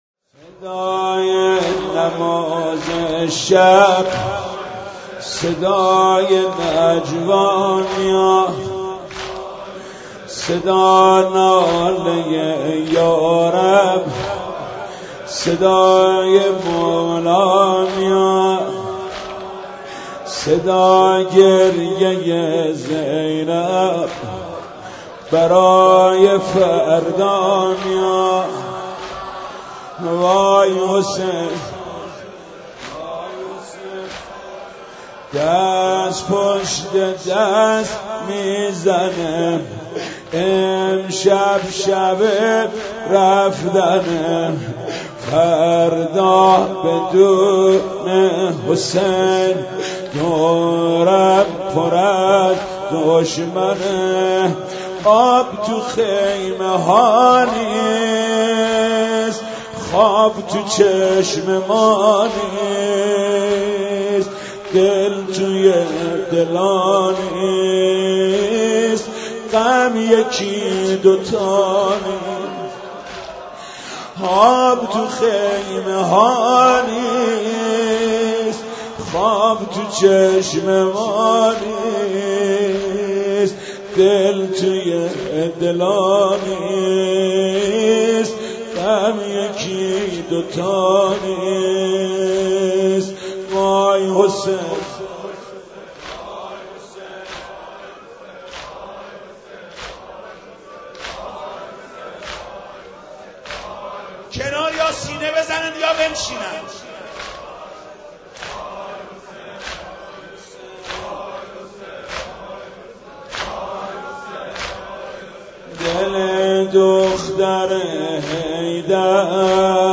(زمینه)